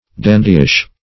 Dandyish \Dan"dy*ish\, a.
dandyish.mp3